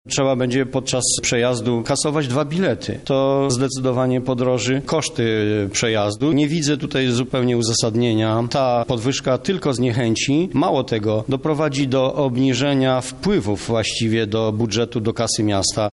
W takim czasie nie da się dojechać do centrum z odległych dzielnic – mówi radny PiS Stanisław Brzozowski.